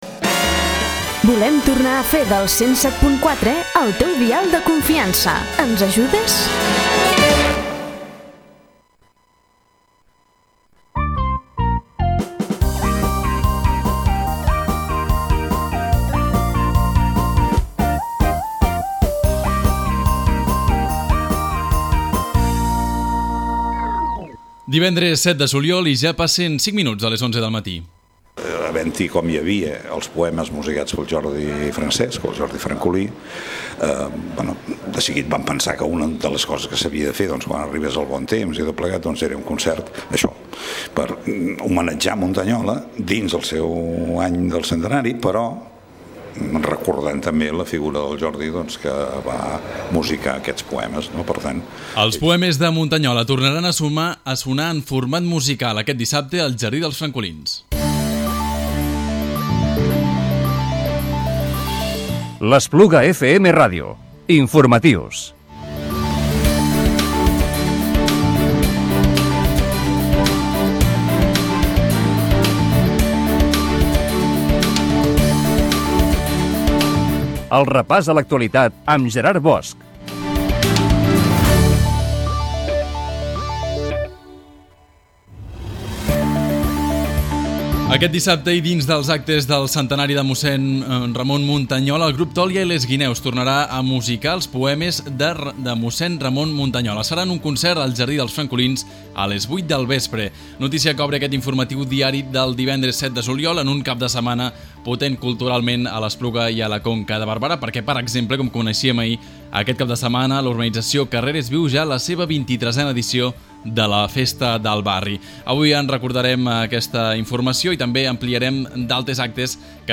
Informatiu-diari-del-divendres-7-de-juliol-del-2017.mp3